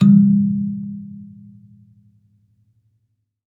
kalimba_bass-G#2-ff.wav